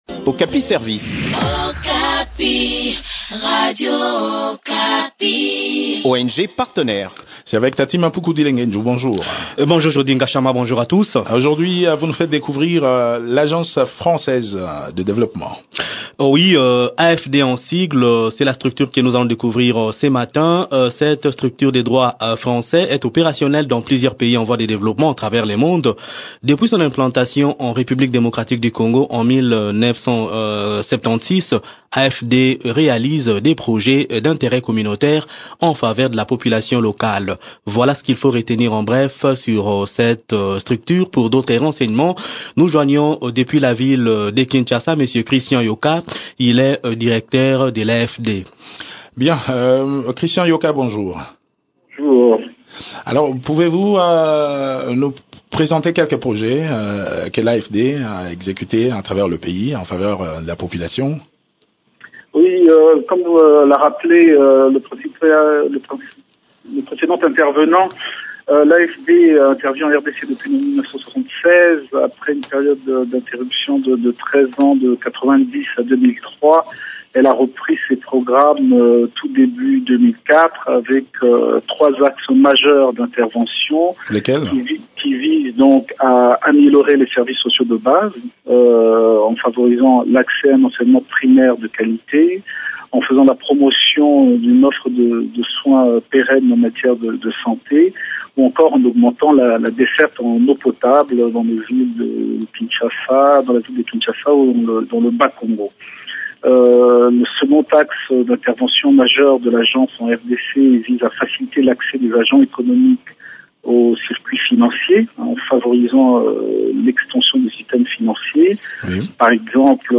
fait le point de leurs activités au micro de